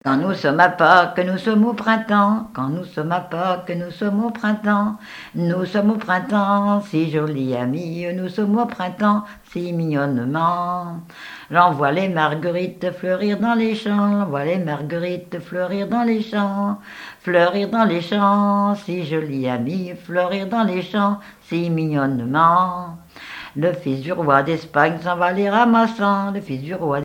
danse : ronde : demi-rond
Pièce musicale inédite